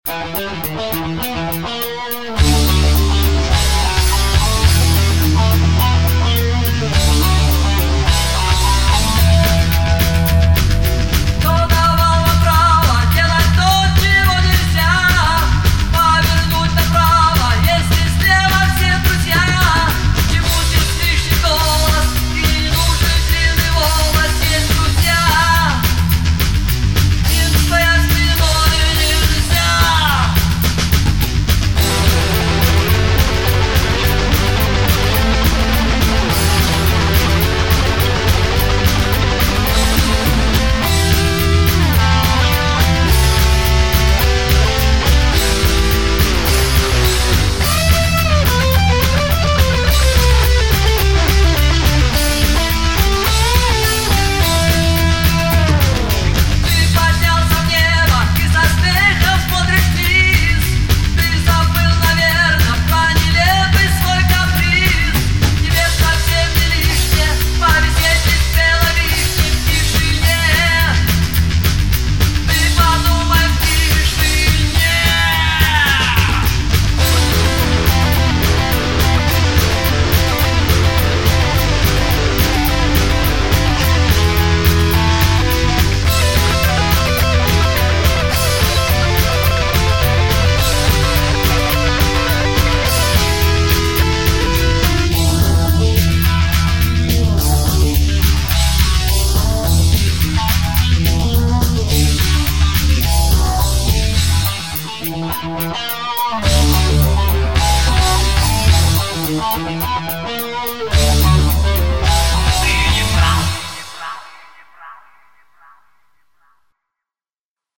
А сведено неплохо!
Спасибо за такую оценку ребята!Это тем более приятно потому что исходным был mp3 с б.т.-160 с приличным шумом и заметным оффсетом.(И ЧЕТКИМ УКАЗАНИЕМ - ЗРОБЫ ШО НИБУДЬ !)